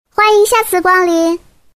萝莉音欢迎下次光临音效_人物音效音效配乐_免费素材下载_提案神器
萝莉音欢迎下次光临音效免费音频素材下载